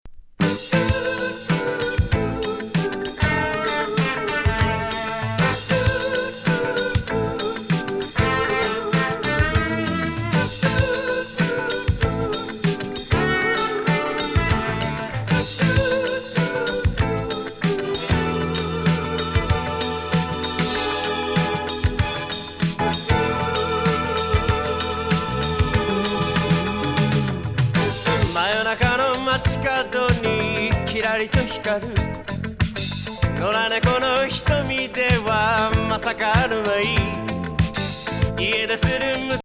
ファンキー和ディスコ
フィリー風ありレゲ風あり。